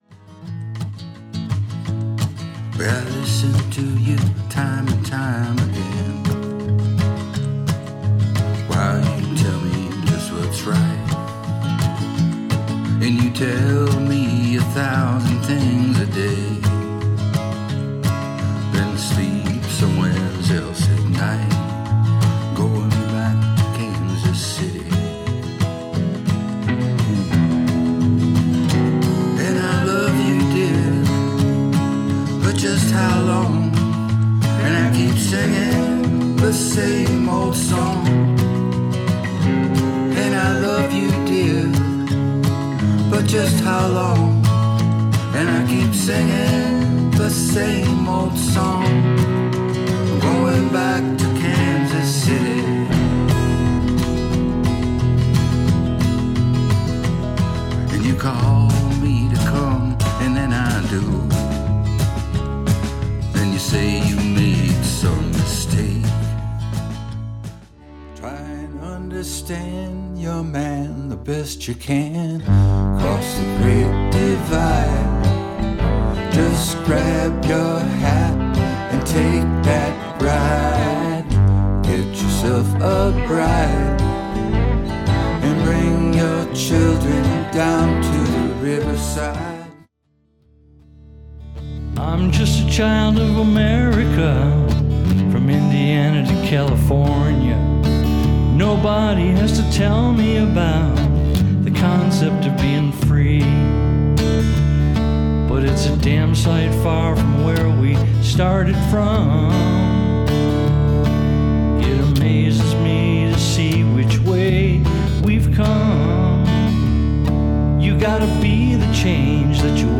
some blues and a few originals.
rehearsing for a special evening